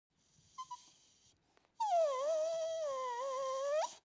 mixkit-dog-sad-whimper-467.wav